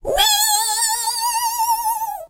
rt_ulti_vo_03.ogg